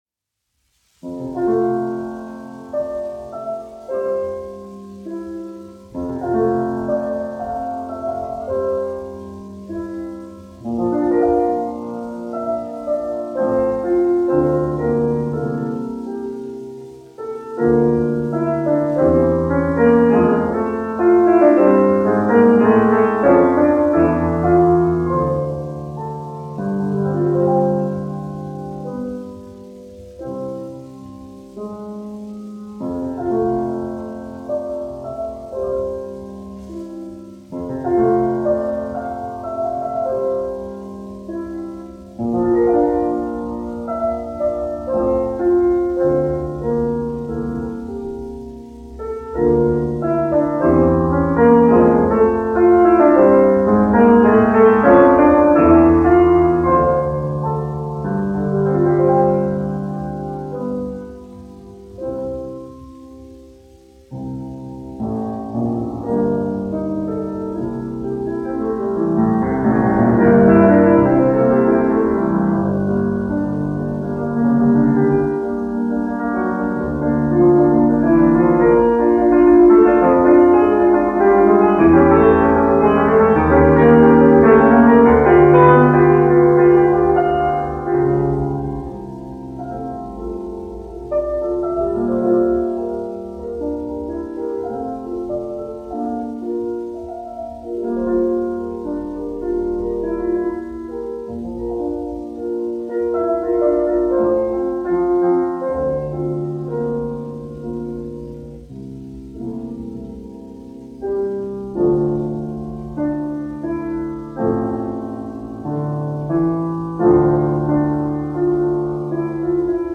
1 skpl. : analogs, 78 apgr/min, mono ; 25 cm
Klavieru mūzika
Latvijas vēsturiskie šellaka skaņuplašu ieraksti (Kolekcija)